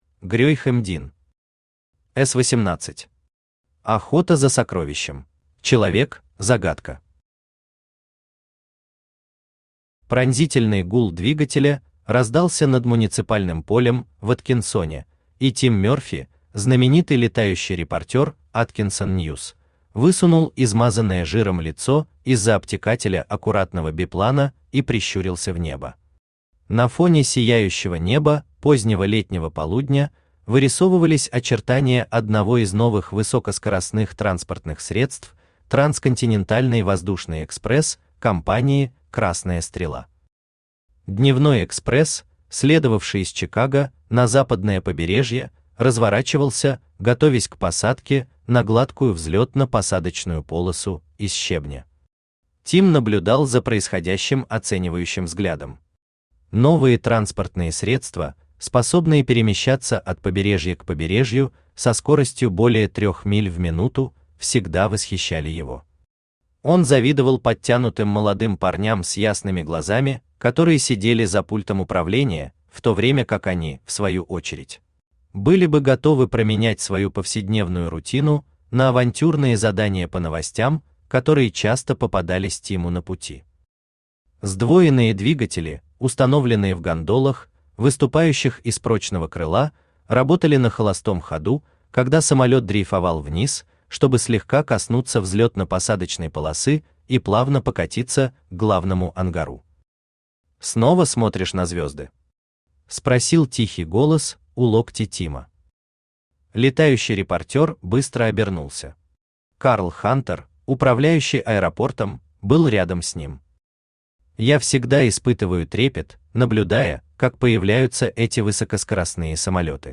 Aудиокнига S-18. Охота за сокровищем Автор Грейхэм М. Дин Читает аудиокнигу Авточтец ЛитРес.